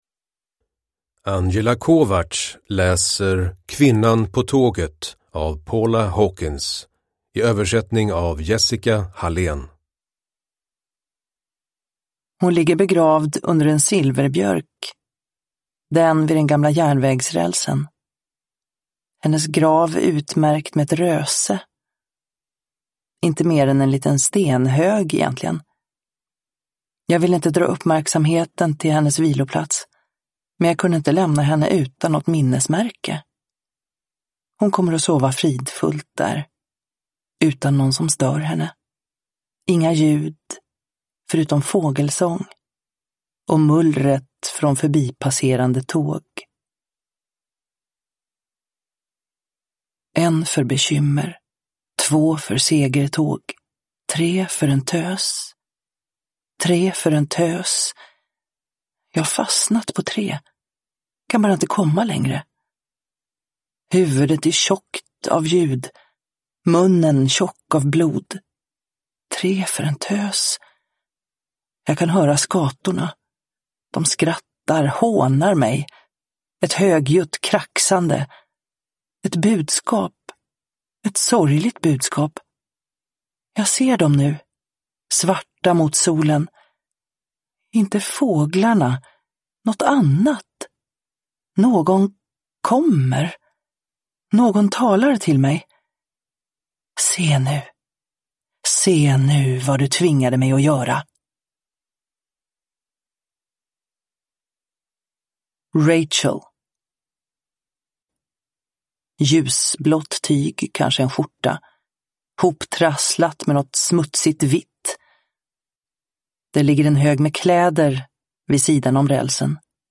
Kvinnan på tåget – Ljudbok – Laddas ner